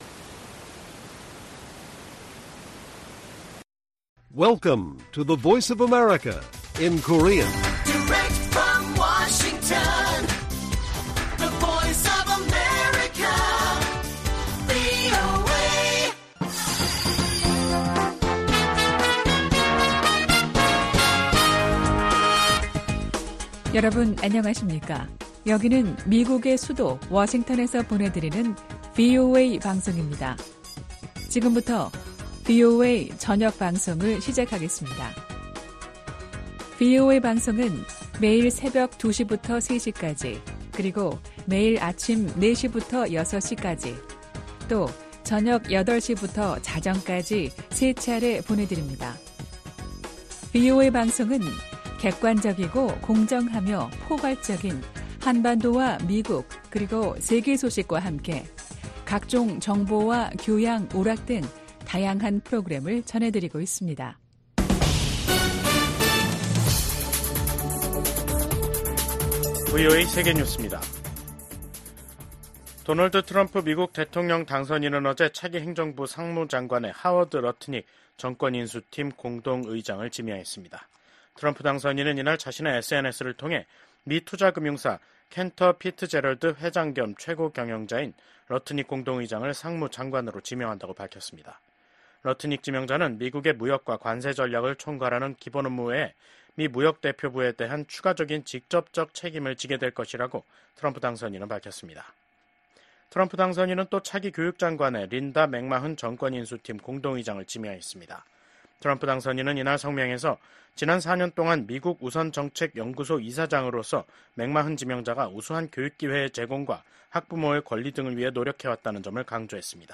VOA 한국어 간판 뉴스 프로그램 '뉴스 투데이', 2024년 11월 20일 1부 방송입니다. 한국 정보 당국은 북한 군이 러시아 군에 배속돼 우크라이나 전쟁에 참여하고 있다고 밝혔습니다. 미국 국방부는 러시아의 우크라이나 침략 전쟁에 참전하는 북한군은 정당한 합법적인 공격 목표가 될 것이라고 재차 경고했습니다. 미국의 우크라이나 전문가들은 북한군 파병이 러시아-우크라이나 전쟁에 미치는 영향이 제한적인 것이라고 전망했습니다.